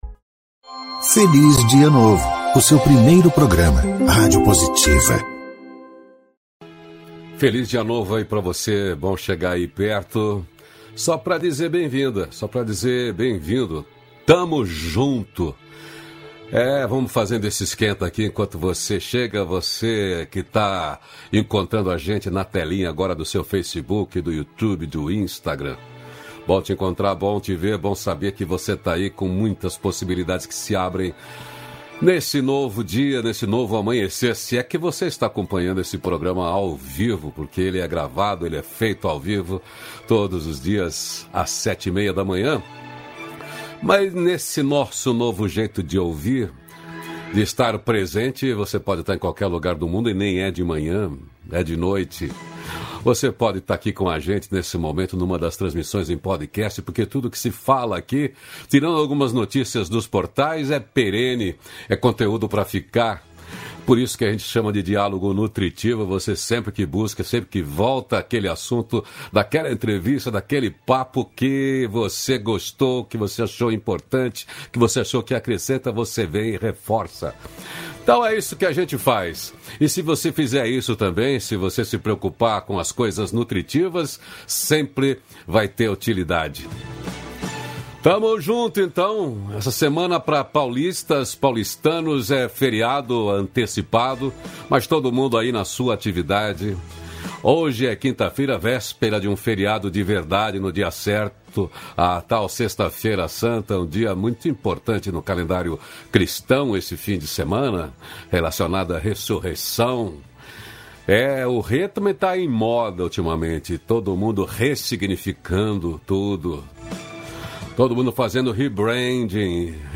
362-feliz-dia-novo-entrevista.mp3